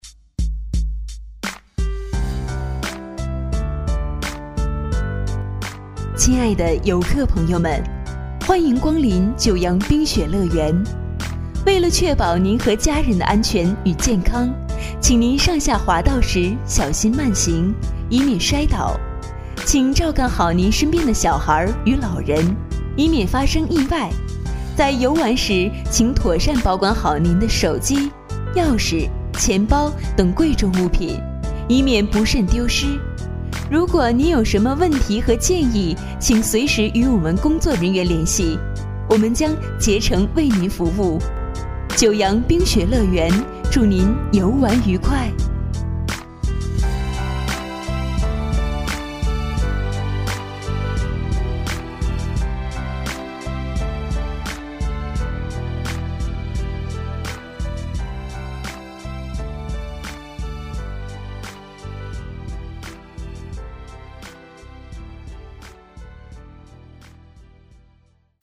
移动水上乐园之九洋冰雪乐园广播
移动水上乐园之九洋冰雪乐园温馨提示广播词